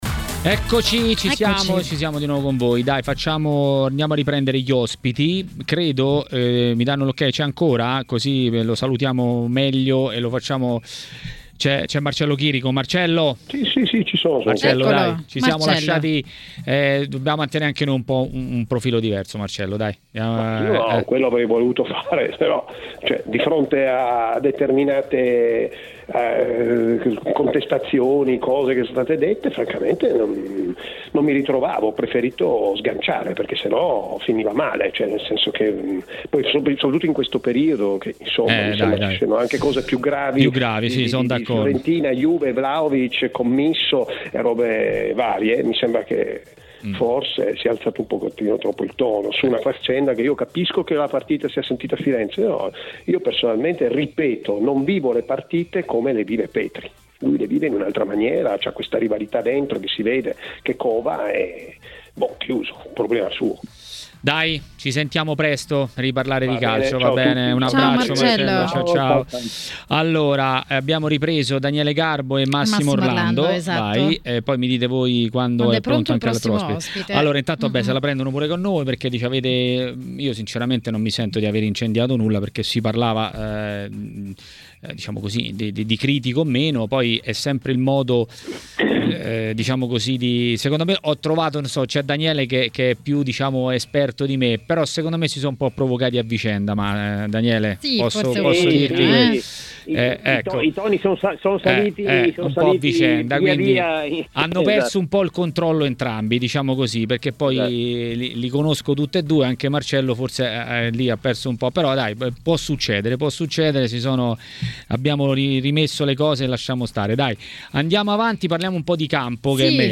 A commentare le notizie del giorno a Maracanà, trasmissione di TMW Radio